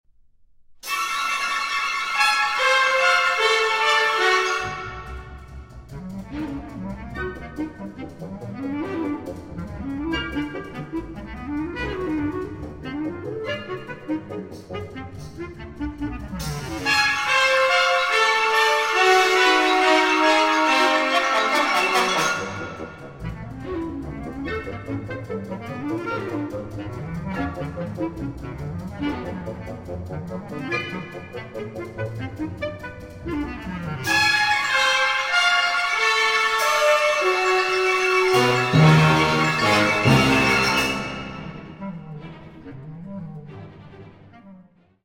Allegro (3:20)